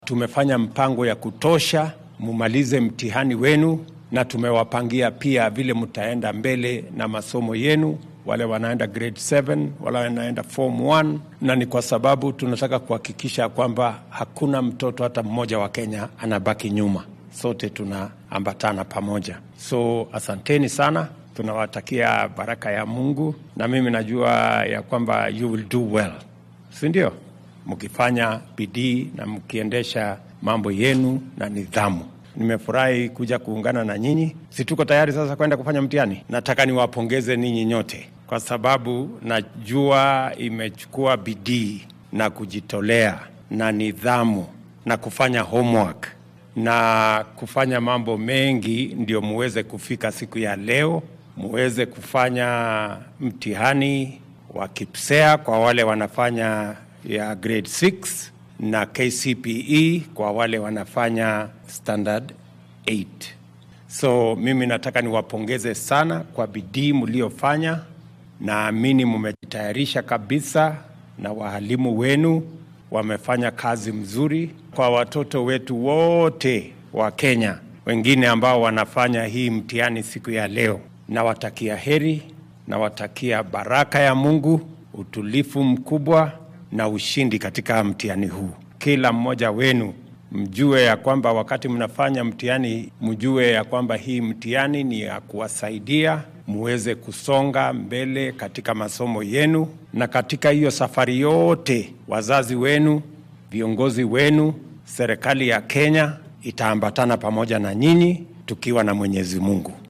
Madaxweynaha dalka William Ruto ayaa xilli uu ku sugnaa dugsiga hoose dhexe ee deegaanka Kikuyu Township ee ismaamulka Kiambu sheegay in dowladdu ay ku dadaali doonto in dhammaan carruurta kenyaanka ah ay fursad u helaan waxbarashada. Waxaa uu ardayda billaabay inay sameeyaan imtixaannada KCPE-da iyo KPSEA ku boorriyay inay isku kalsoonaadaan oo ay inta karaankooda ah dadaalaan. Hoggaamiyaha dalka ayaa ardayda u sheegay in guusha ay ku xiran tahay dadaal, habdhaqan wanaagsan iyo qadarinta macallimiinta iyo waalidiinta.